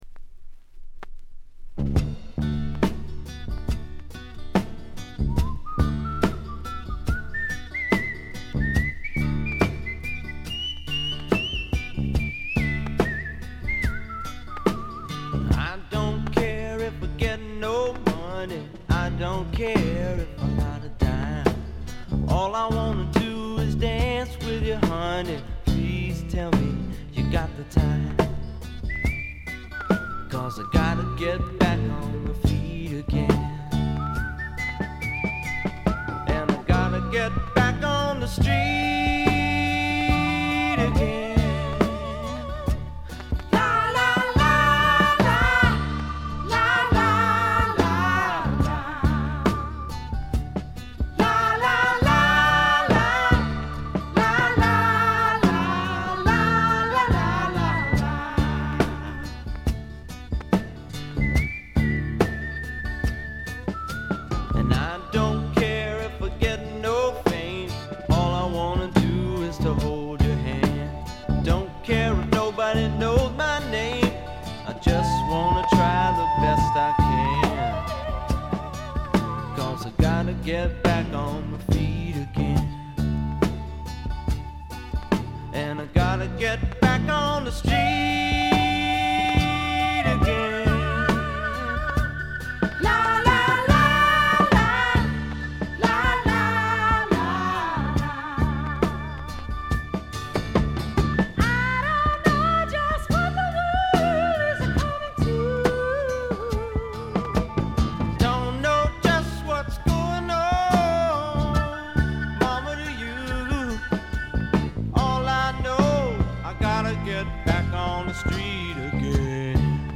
A2で軽微なものですが周回気味のチリプチが出ます。
基本は軽いスワンプ路線。
試聴曲は現品からの取り込み音源です。